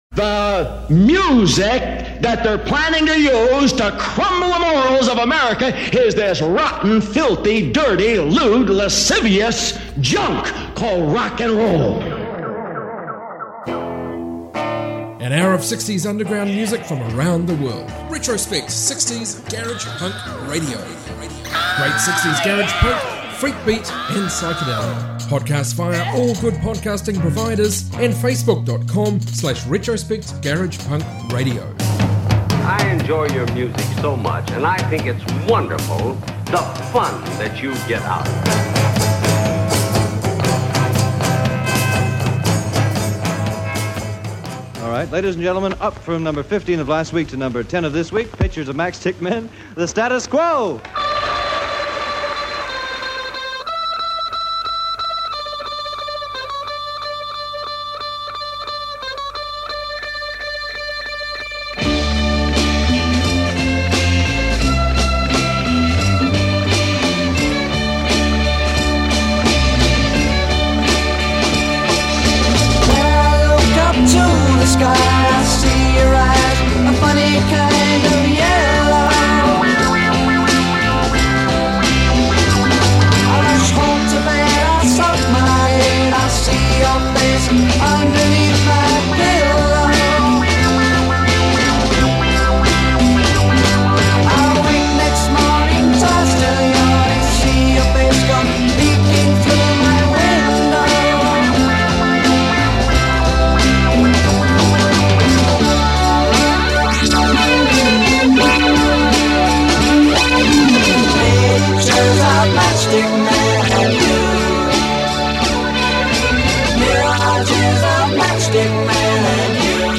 60s Garage Rock Garage Punk Freakbeat Psychedelia